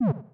drop.ogg